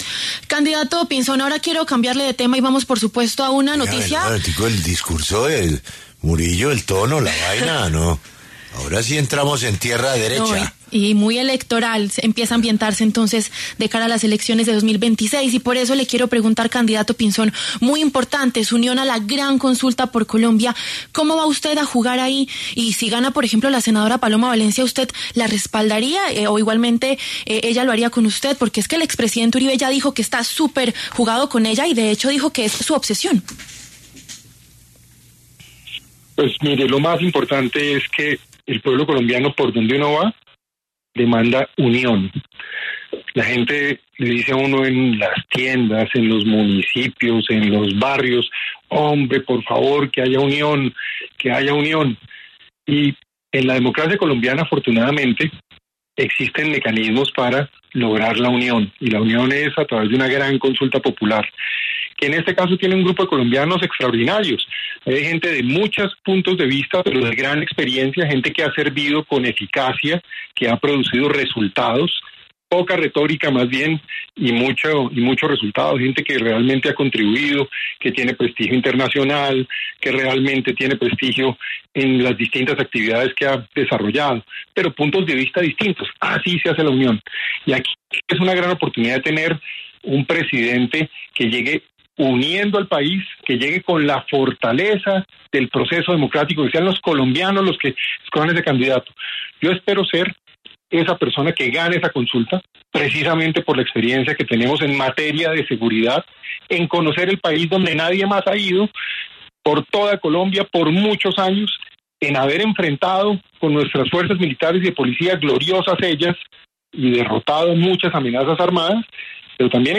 El precandidato Juan Carlos Pinzón pasó por los micrófonos de La W y se refirió a su unión a la Gran Consulta por Colombia.